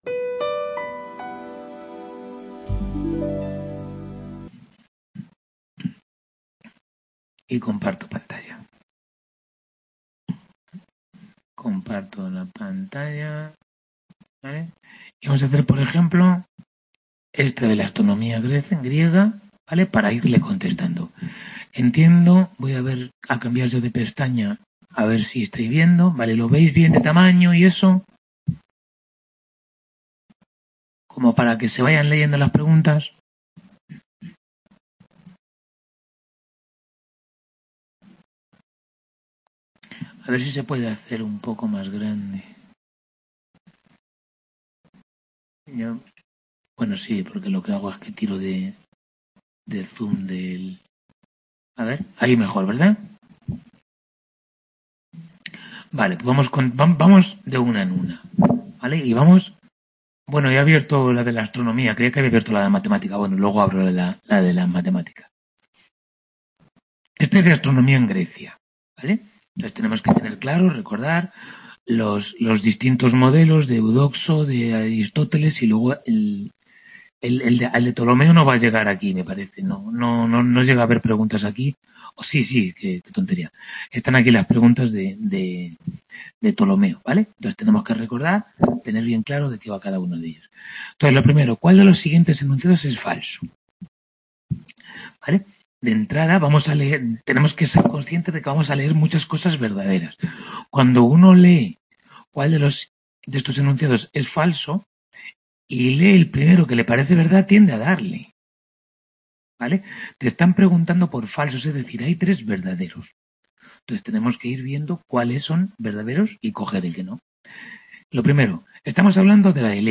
Tutoría 12 de Historia General de la Ciencia I | Repositorio Digital